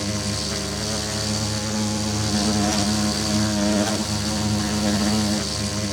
minecraft / sounds / mob / bee / loop1.ogg